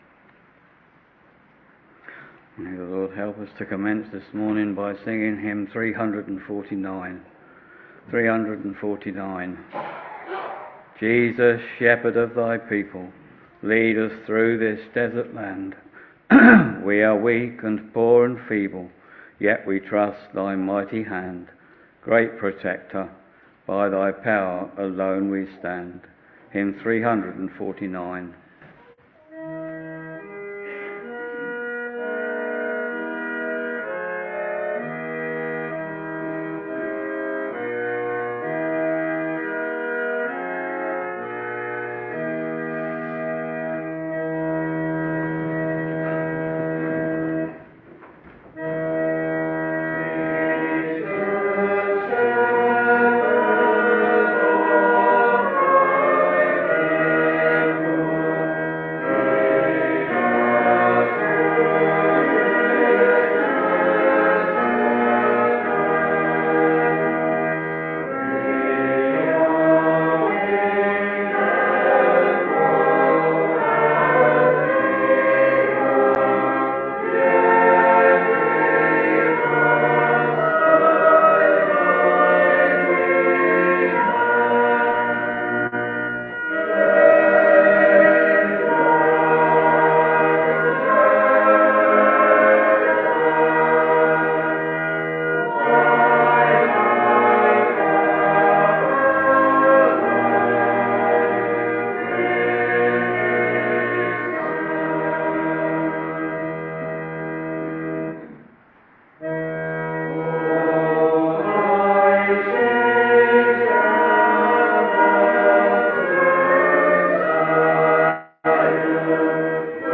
Sunday, 1st December 2024 — Morning Service Preacher